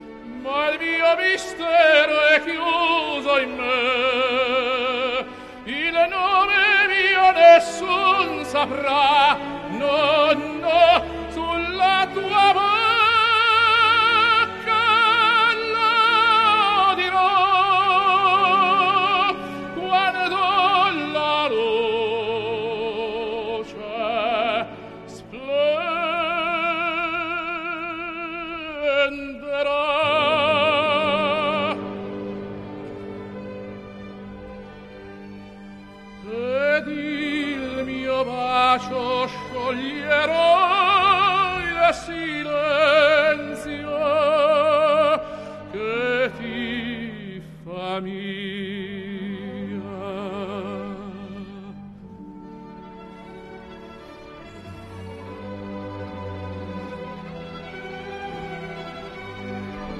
at the ancient Baths of Caracalla in Rome